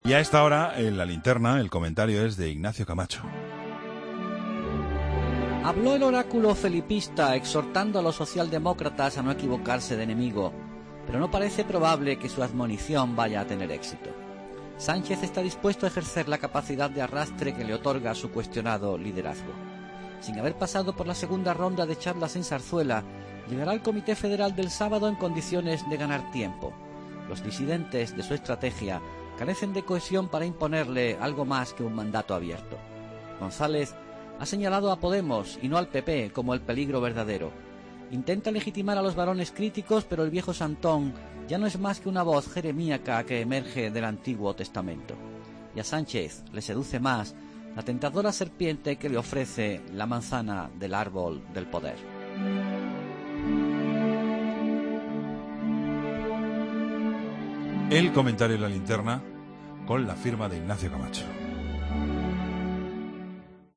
AUDIO: Comentario de Ignacio Camacho en La Linterna